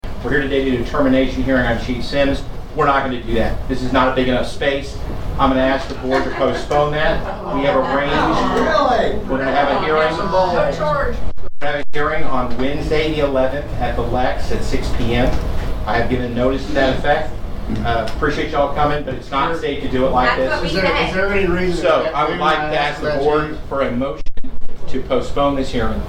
The decision to delay the hearing, initially called for the evening of Wednesday, Aug. 29th came at the recommendation of city attorney Todd Smith, who cited capacity issues at City Hall: